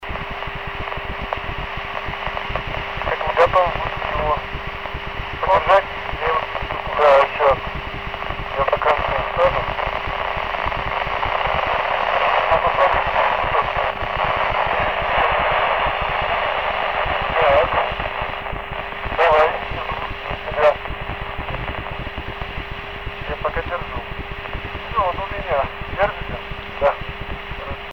Начало » Записи » Записи радиопереговоров - МКС, спутники, наземные станции
24 июня 2013 на балконе с резинкой ФТ60